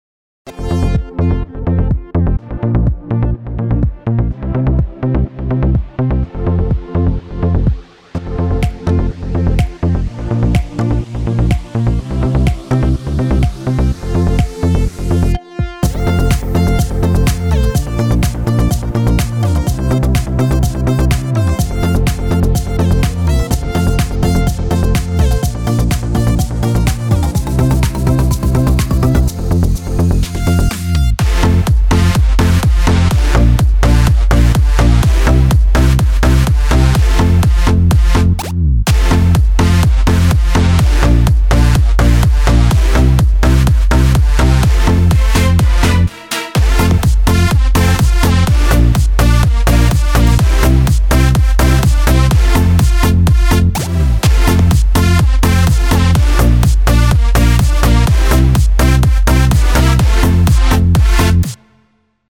אני לא יודע מה נקרא פרוגרסיב האוס תגידו לי אם זה זה